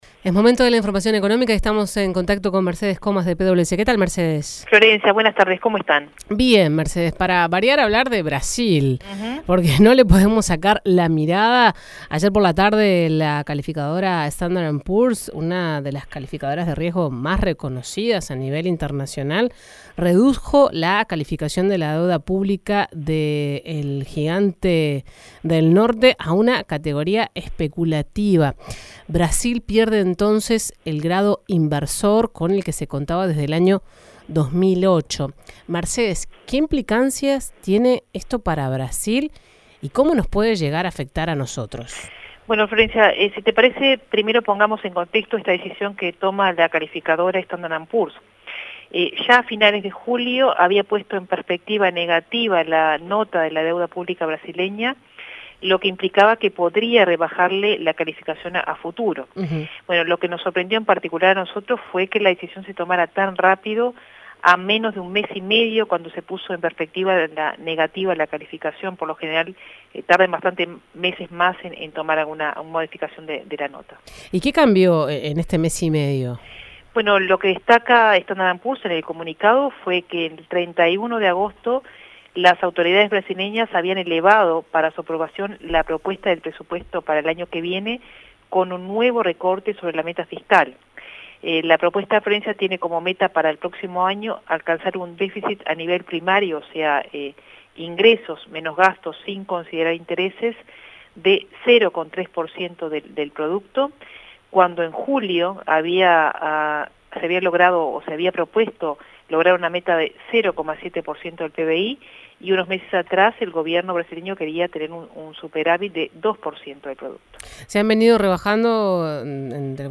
Análisis de PWC